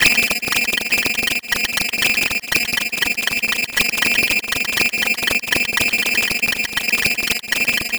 Abstract Rhythm 07.wav